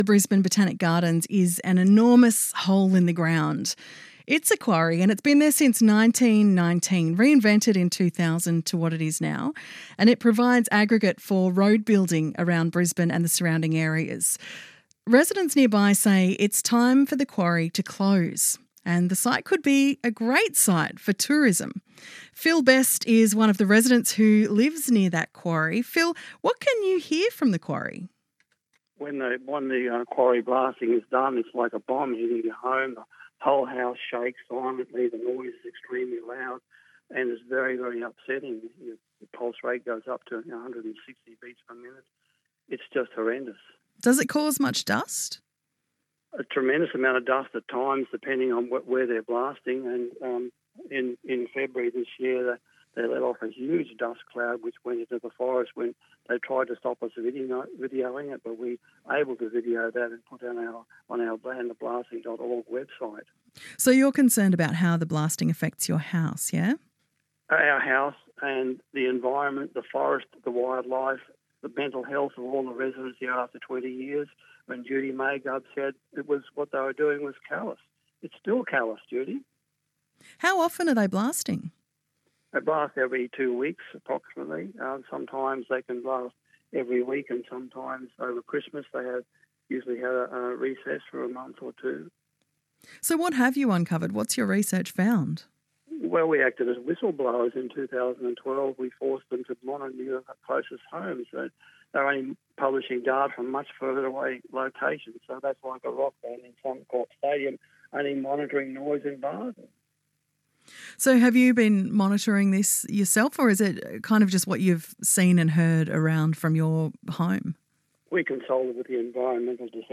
ABC Brisbane Radio Afternoon Show: Follow-up Interview